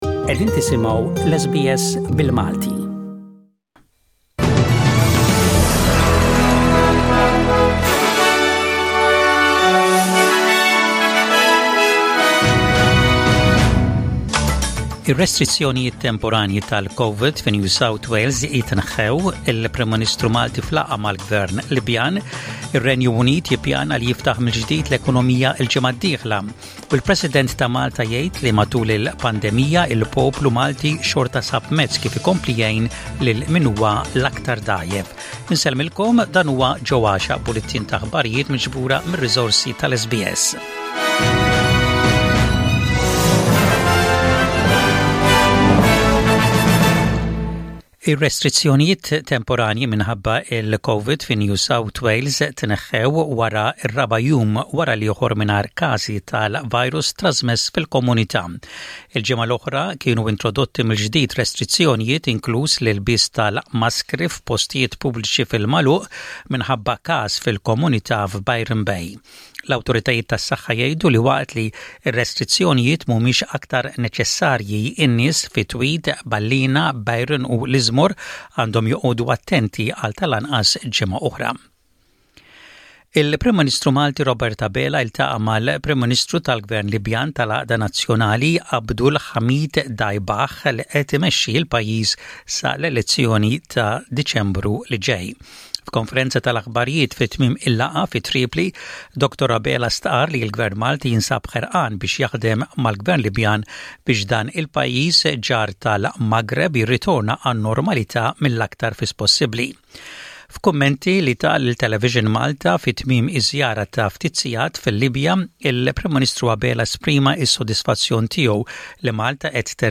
SBS Radio | Maltese News: 06/04/21